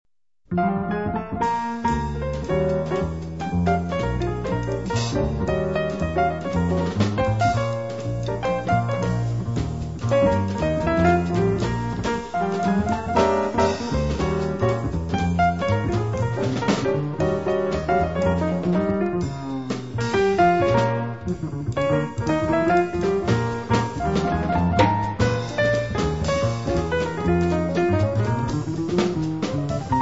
bass
drums
piano
• jazz